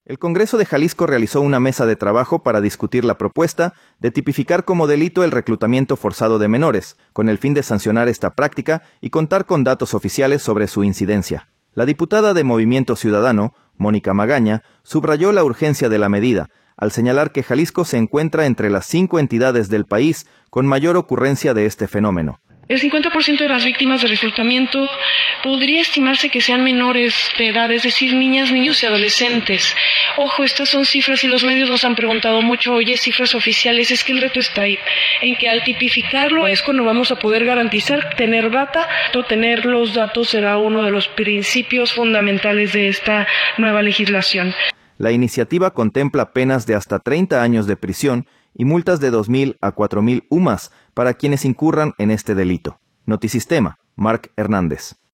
audio El Congreso de Jalisco realizó una mesa de trabajo para discutir la propuesta de tipificar como delito el reclutamiento forzado de menores, con el fin de sancionar esta práctica y contar con datos oficiales sobre su incidencia. La diputada de Movimiento Ciudadano, Mónica Magaña, subrayó la urgencia de la medida, al señalar que Jalisco se encuentra entre las cinco entidades del país con mayor ocurrencia de este fenómeno.